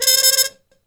Index of /90_sSampleCDs/E-MU Formula 4000 Series Vol. 1 - Hip Hop Nation/Default Folder/Trumpet MuteFX X